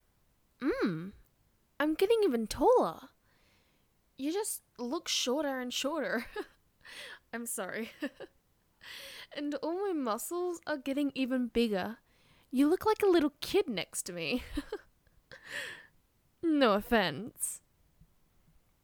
(Please note the lines were individually recorded and spliced together)